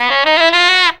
JAZZY B.wav